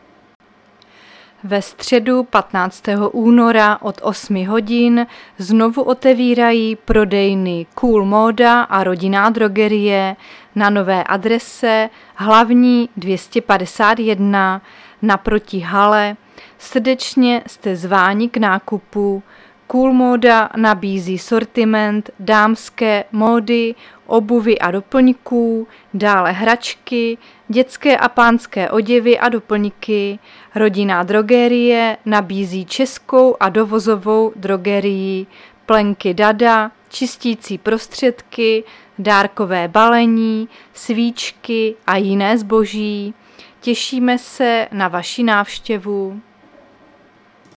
Záznam hlášení místního rozhlasu 15.2.2023